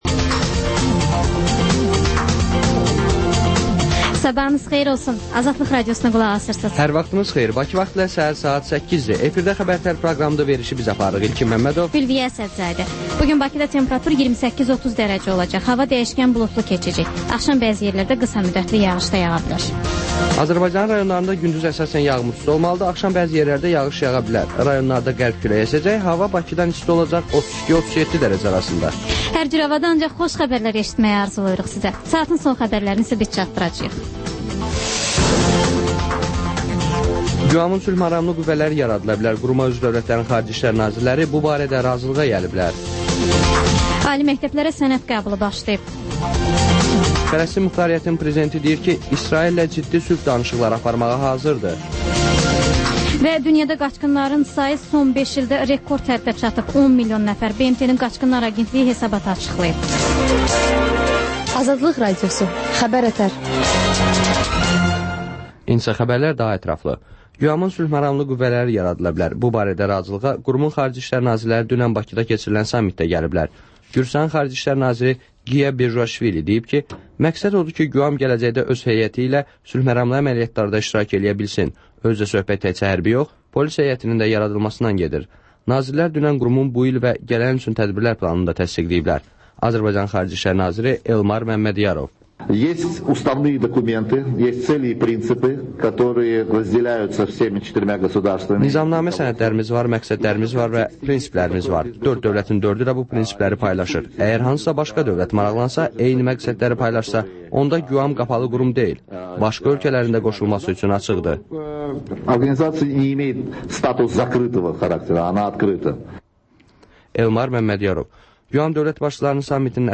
Xəbər-ətər: xəbərlər, müsahibələr, sonda XÜSUSİ REPORTAJ rubrikası: Ölkənin ictimai-siyasi həyatına dair müxbir araşdırmaları